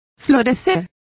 Complete with pronunciation of the translation of burgeon.